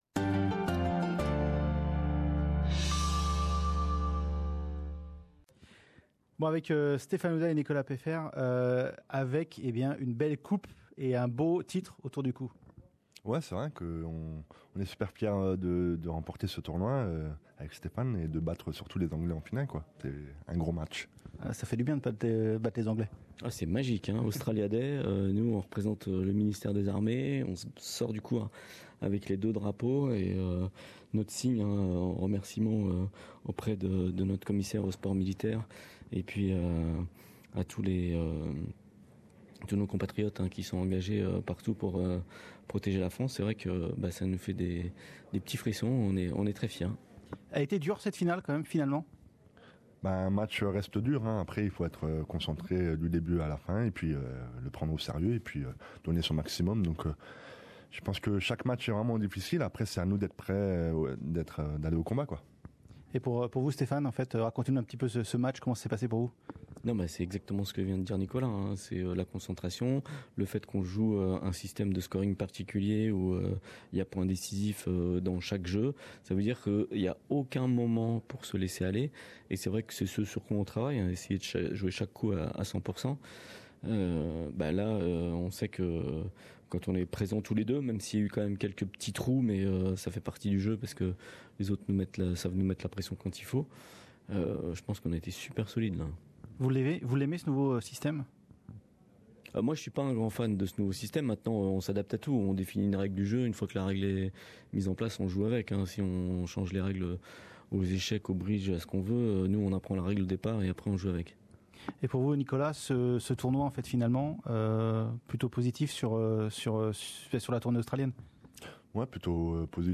Entretien avec Stephane Houdet et Nicolas Peifer, vainqueurs d'un nouveau grand chelem a l'Open d'Australie.